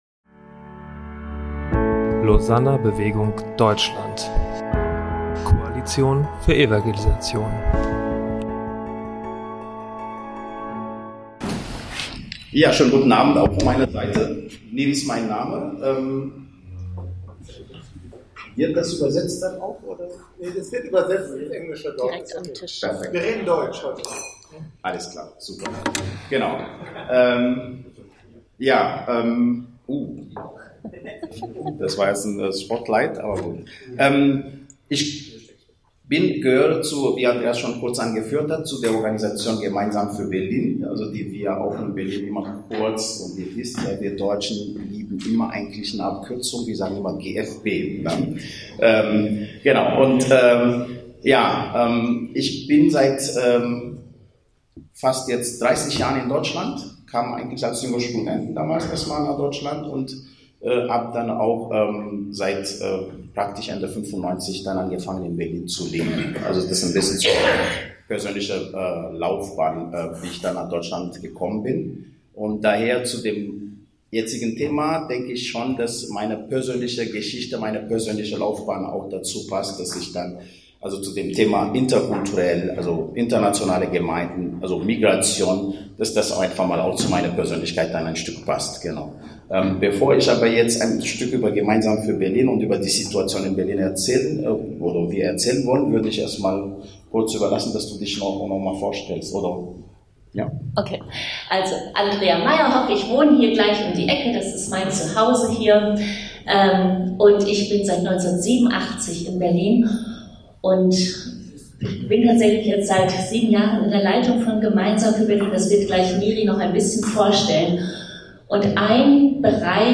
beim Lausanner Forum 2023 ~ Lausanner Bewegung Podcast